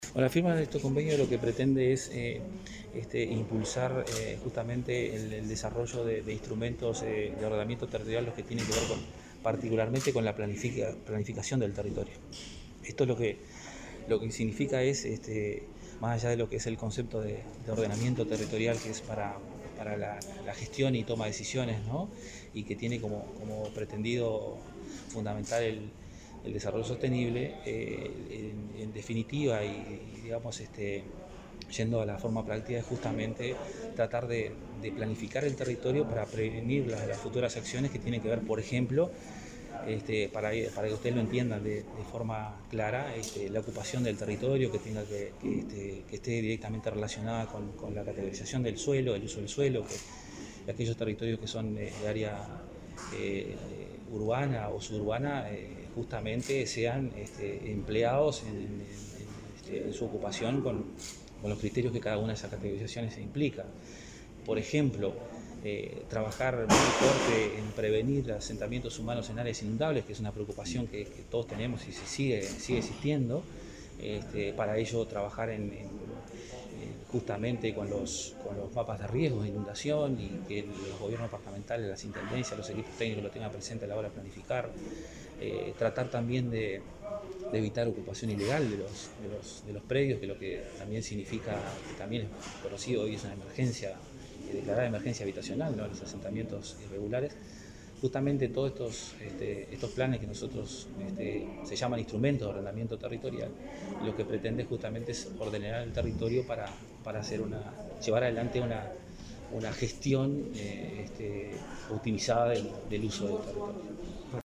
Declaraciones del director general de Ordenamiento Territorial, Norbertino Suárez